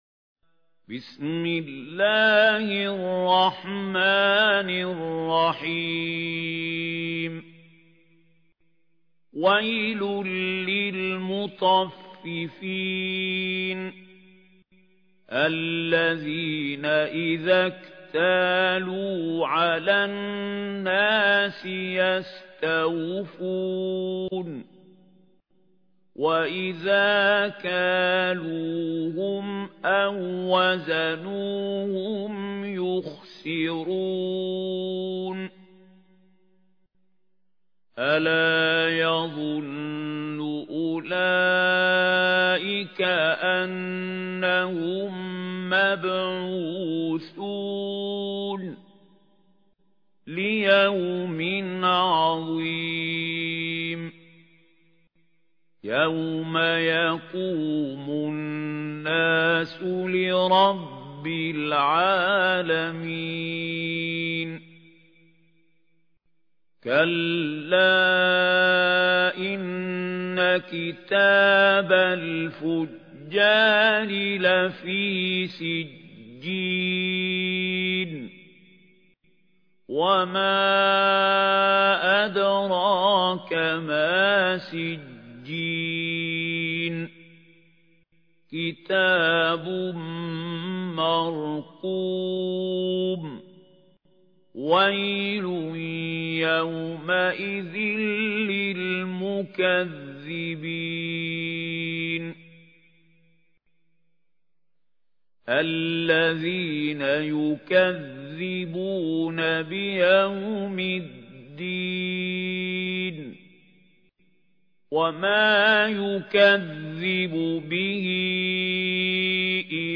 ترتيل
سورة المطفّفين الخطیب: المقريء محمود خليل الحصري المدة الزمنية: 00:00:00